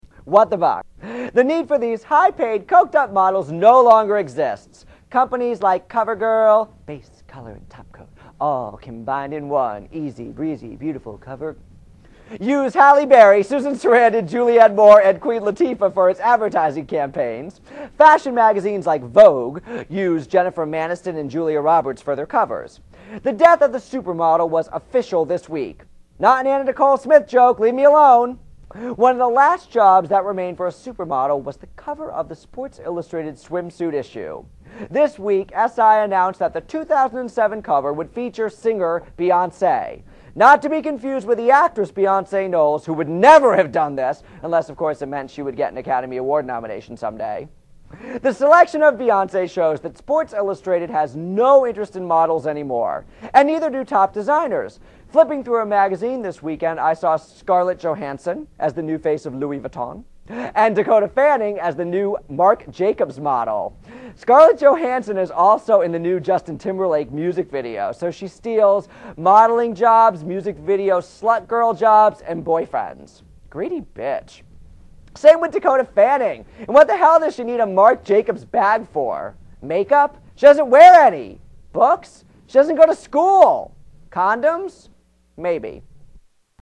Tags: Television What the Buck Show Michael Buckley Celebrity News News Program